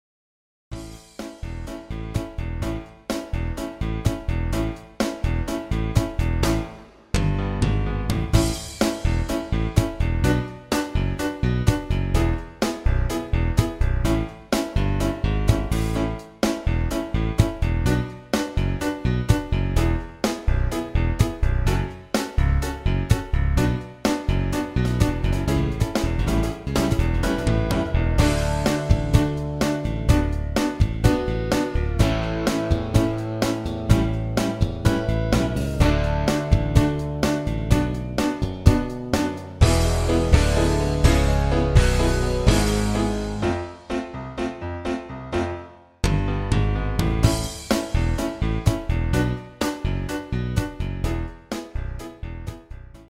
פלייבק איכותי – תואם מקור